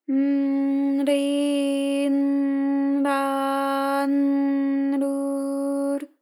ALYS-DB-001-JPN - First Japanese UTAU vocal library of ALYS.
r_n_ri_n_ra_n_ru_r.wav